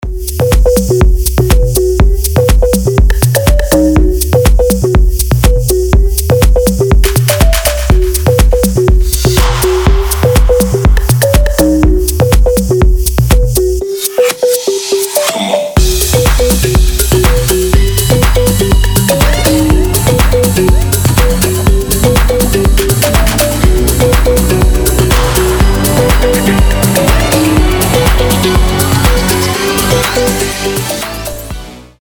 Стиль: deep progressive house Ура!